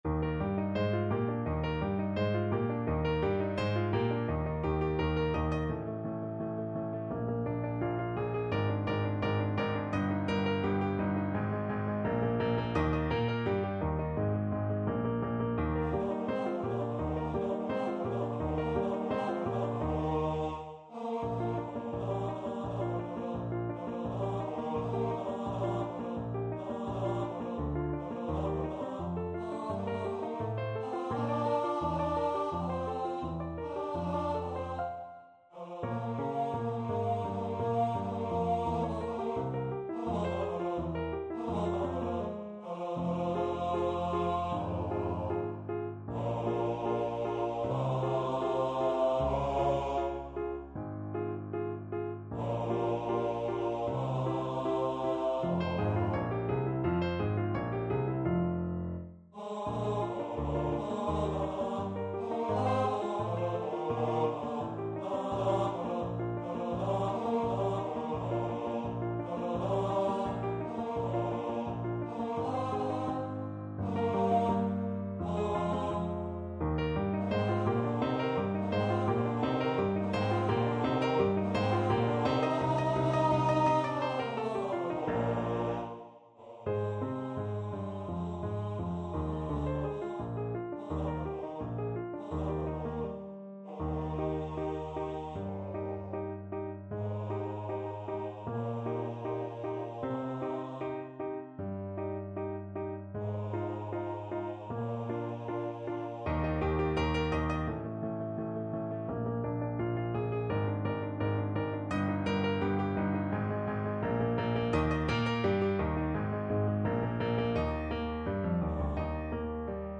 ~ = 170 Tempo giusto
4/4 (View more 4/4 Music)
Classical (View more Classical Bass Voice Music)